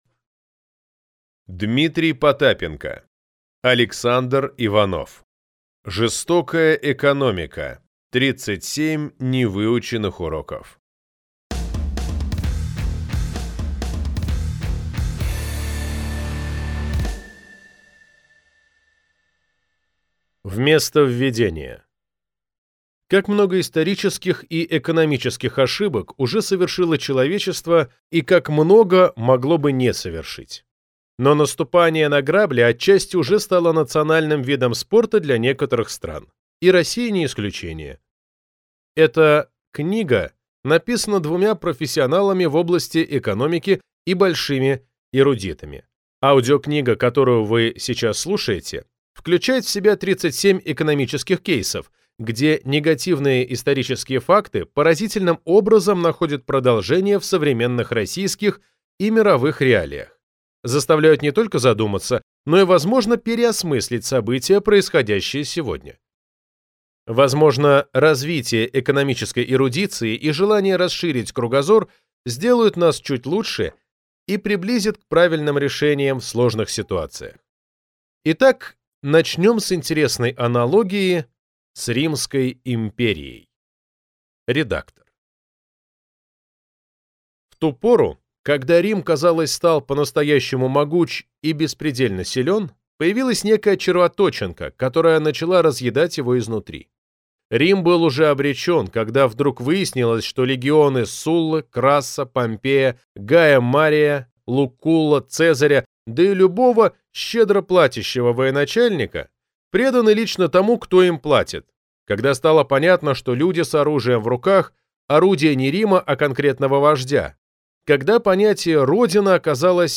Аудиокнига Жестокая экономика. 37 невыученных уроков | Библиотека аудиокниг